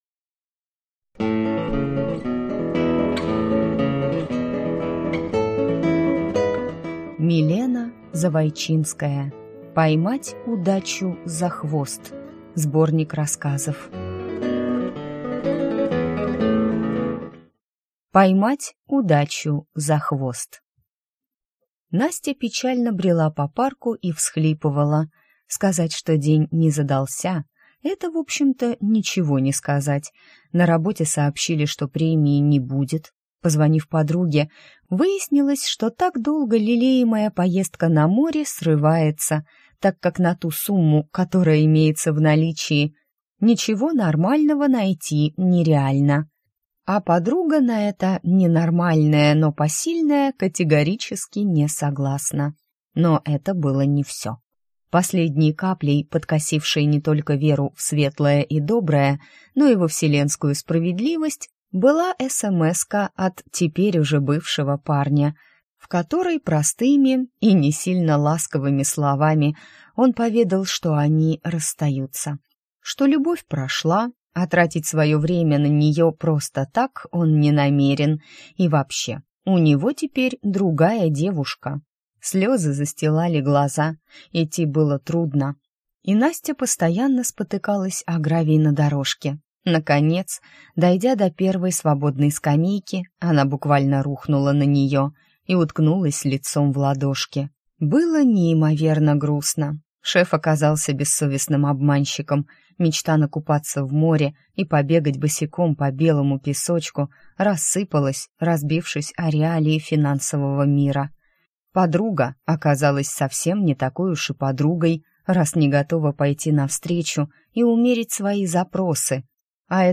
Аудиокнига Поймать Удачу за хвост | Библиотека аудиокниг